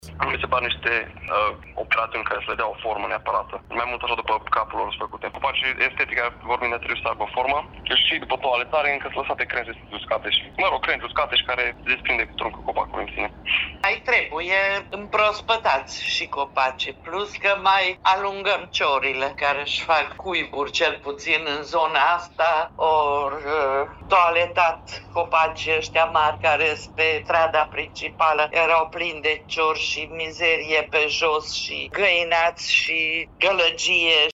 Părerile cetăţenilor cu privire la toaletări sunt împărţite, unii fiind mulţumiţi de activitate, alţii spunând că aceasta nu este estetică: